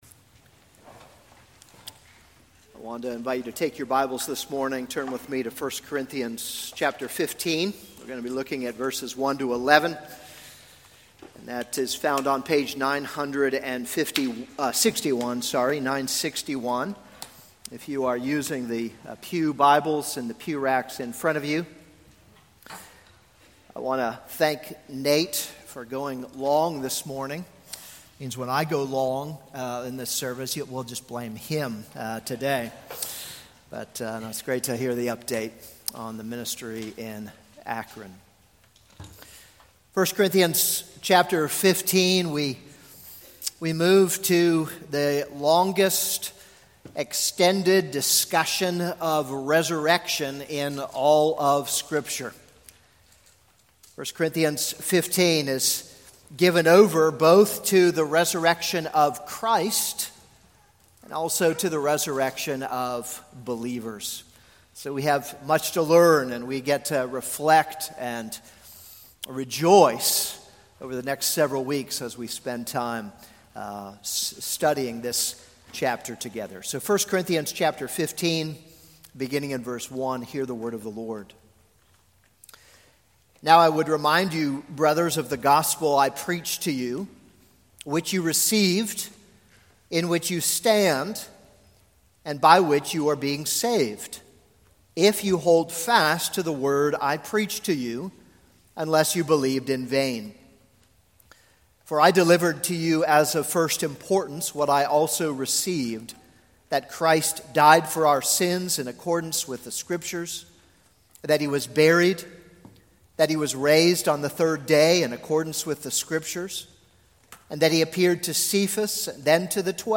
This is a sermon on 1 Corinthians 15:1-11.